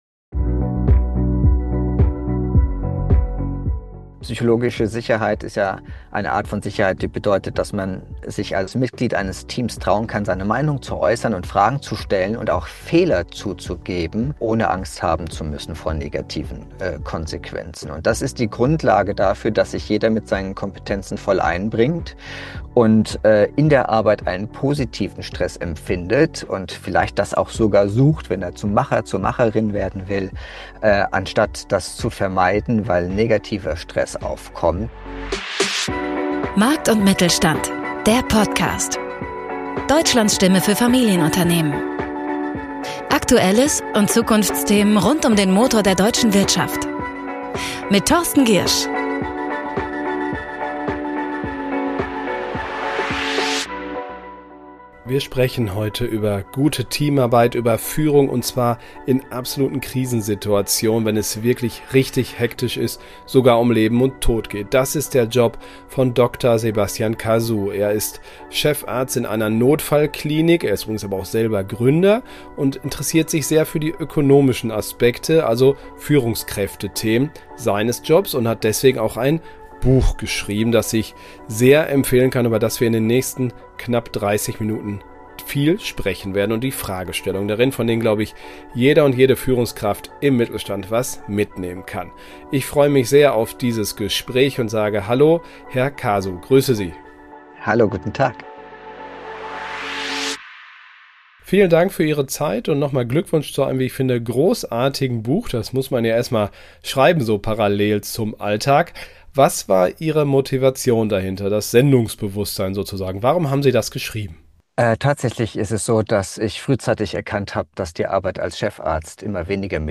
Heute lehrt er Manager, wie man Druck standhält, Entscheidungen trifft und psychologische Sicherheit schafft. Ein Gespräch über Führung im Ausnahmezustand.